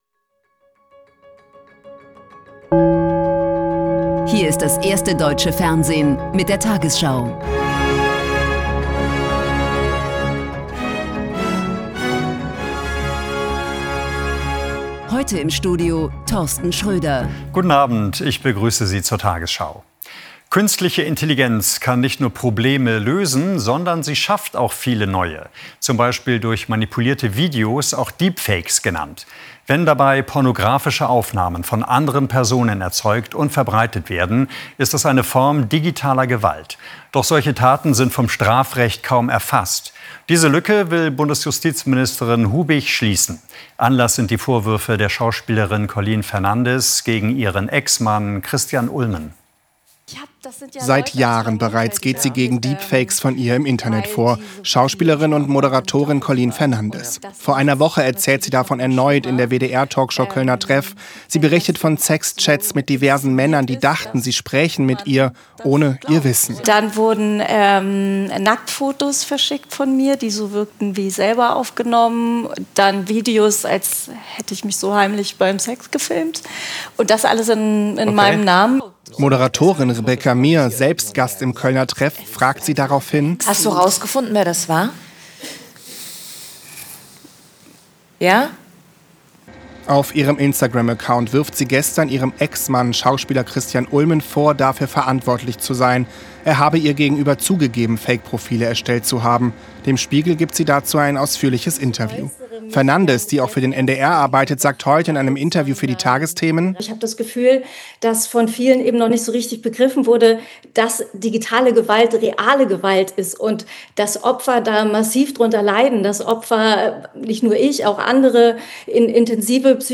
tagesschau 20:00 Uhr, 20.03.2026 ~ tagesschau: Die 20 Uhr Nachrichten (Audio) Podcast